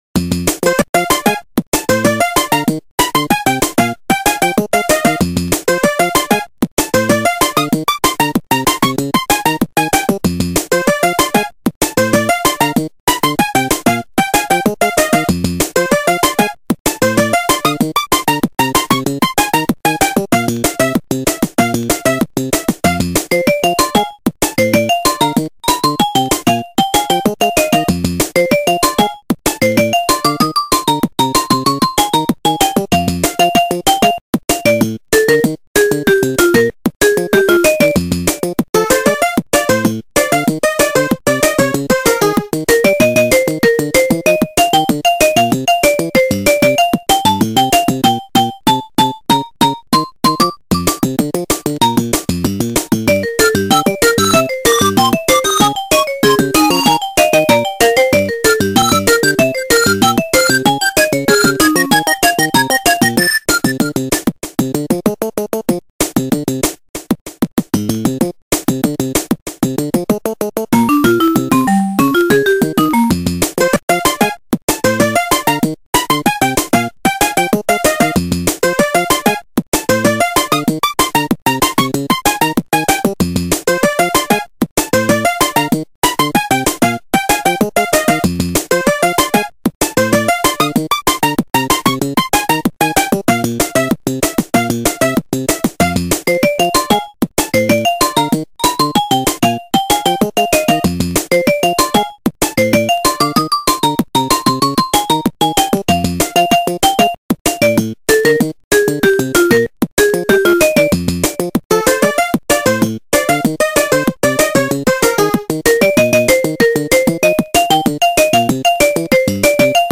>Pelimusa
>>657111 Ihan kuin joku ysärin lopun marioralli.